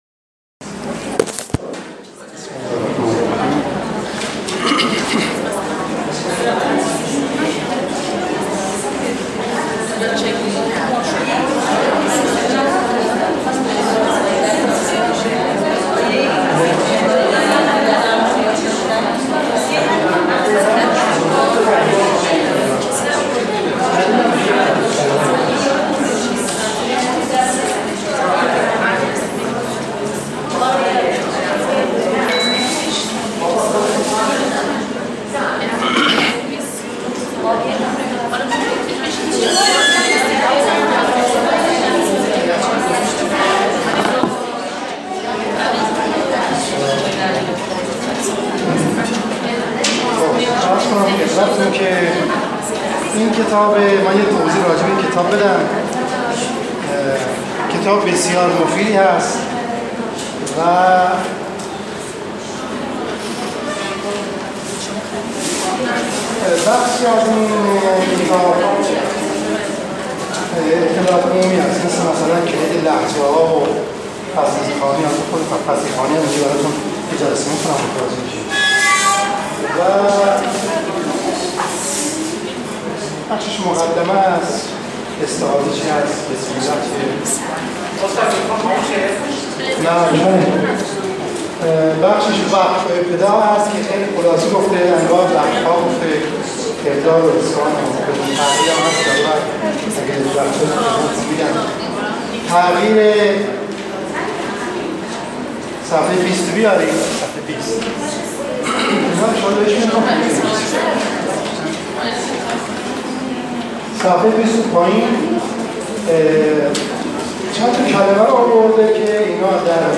دومین جلسه آموزش ضمن خدمت قرآن آموزگاران ابتدایی